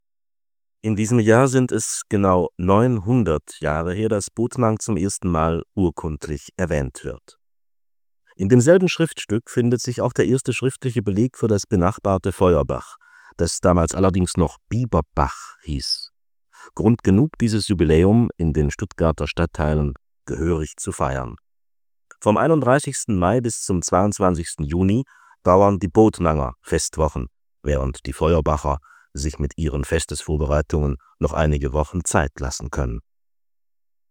Bei der Ausstrahlung der Sendung am 24.05.1975 saß sie mit ihrem Kassettenrecorder vor dem Radio und nahm die ganze Sendung auf.